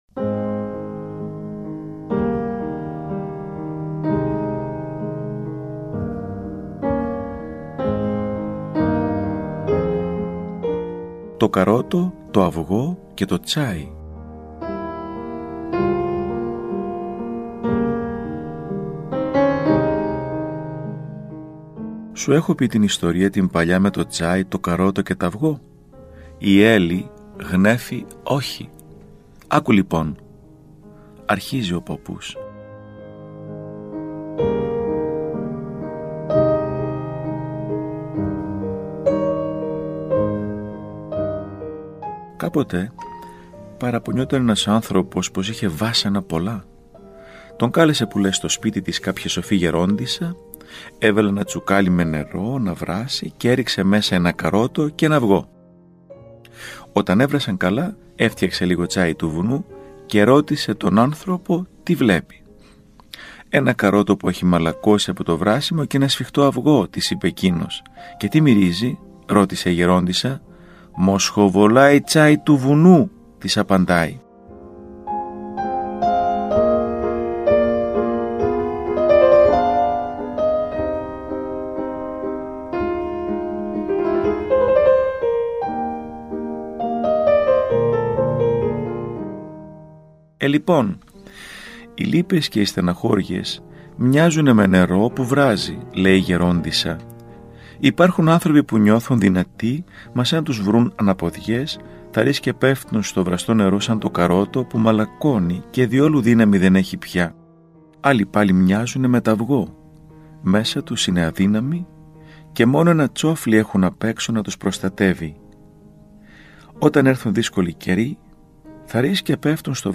Το καρότο, το αυγό και το τσάι (Διδακτική ιστορία. Κείμενο και αρχείο ήχου, mp3).
Ακούστε το επόμενο κείμενο, όπως αυτό “δημοσιεύθηκε” στο 180-ο τεύχος (Νοεμβρίου – Δεκεμβρίου του 2019) του ηχητικού περιοδικού μας, Ορθόδοξη Πορεία.